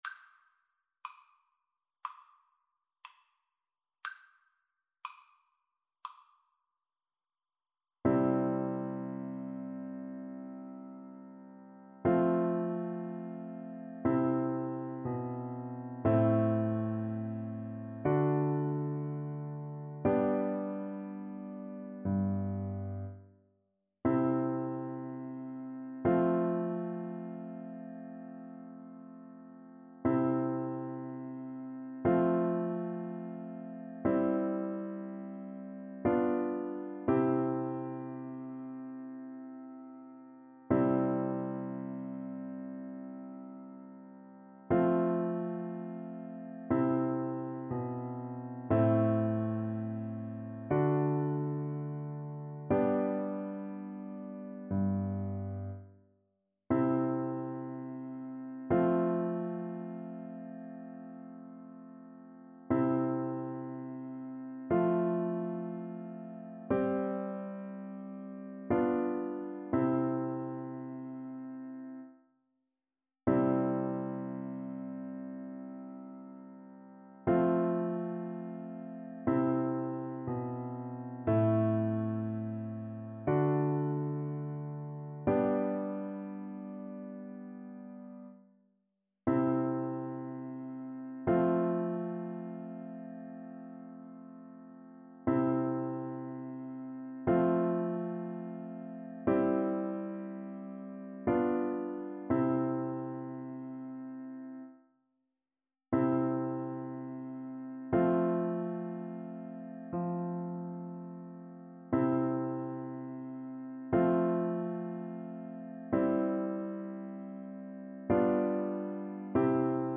Play (or use space bar on your keyboard) Pause Music Playalong - Piano Accompaniment Playalong Band Accompaniment not yet available reset tempo print settings full screen
C major (Sounding Pitch) G major (French Horn in F) (View more C major Music for French Horn )
~ = 100 Adagio
4/4 (View more 4/4 Music)
Classical (View more Classical French Horn Music)